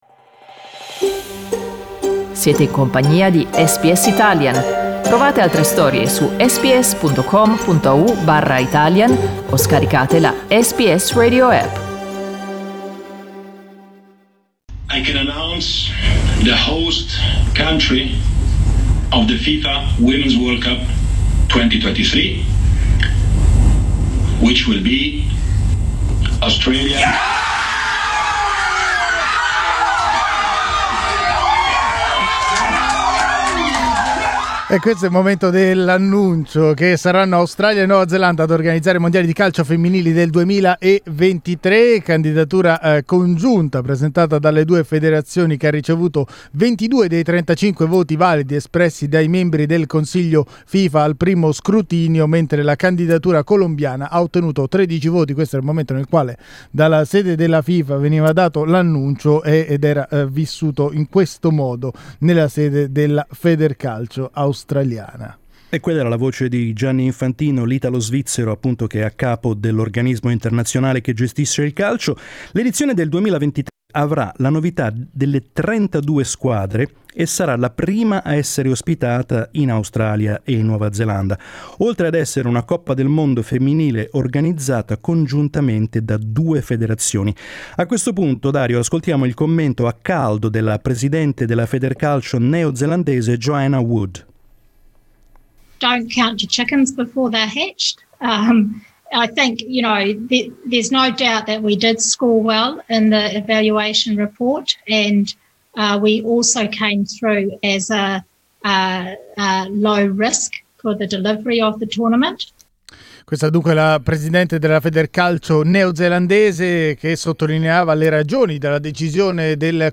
The news of the assignment of the Women's World Cup to Australia and New Zealand was received with great favour across the country, arousing enormous enthusiasm at all levels and this morning we asked our listeners if they feel involved in this "World Cup fever".